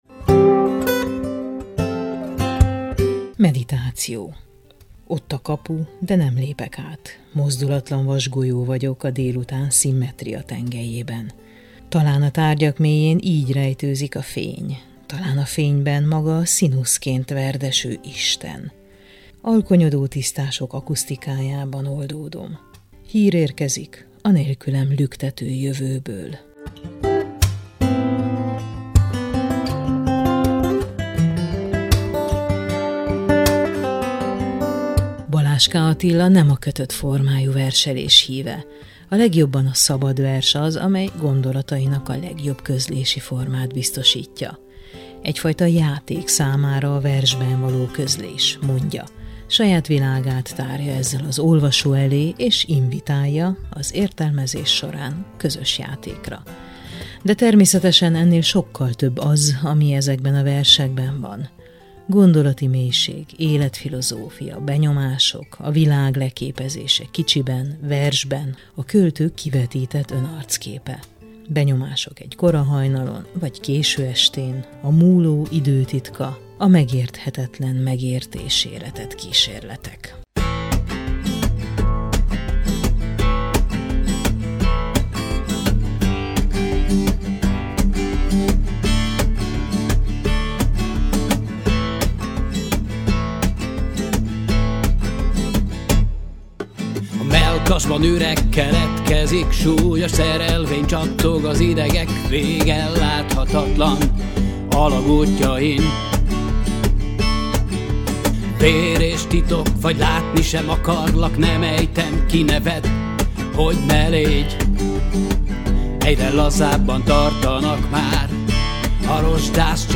beszélgettünk vers és zene kapcsolatáról